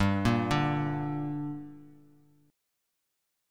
Gm Chord
Listen to Gm strummed